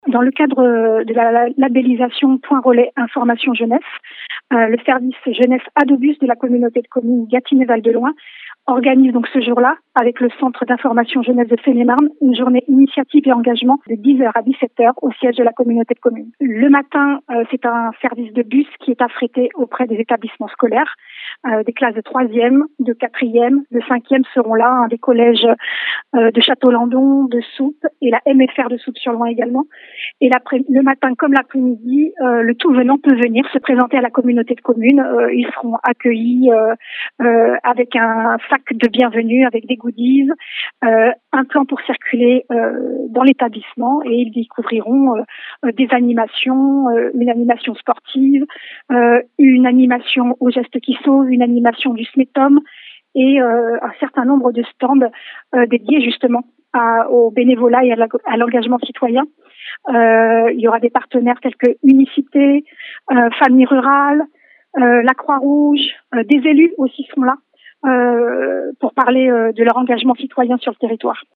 Au micro d'Oxygène ce mardi